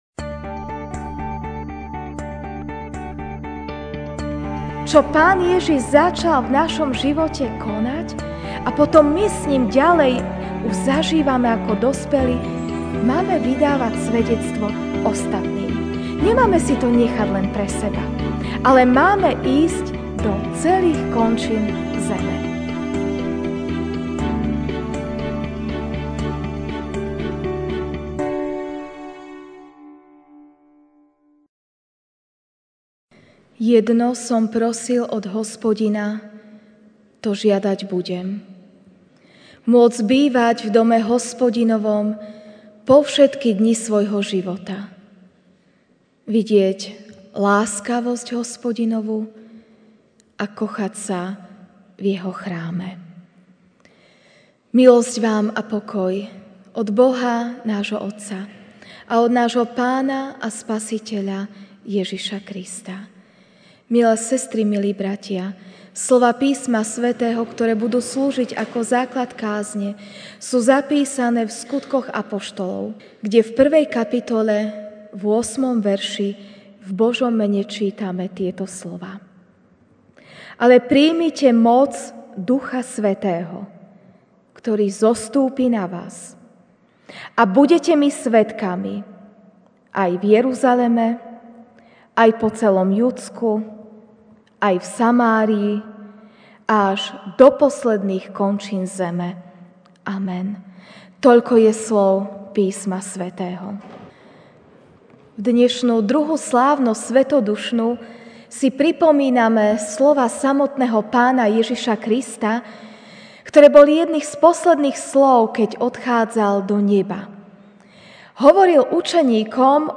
Večerná kázeň: Budete mi svedkami (Sk 1, 8)